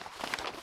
x_enchanting_scroll.4.ogg